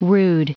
Prononciation du mot rood en anglais (fichier audio)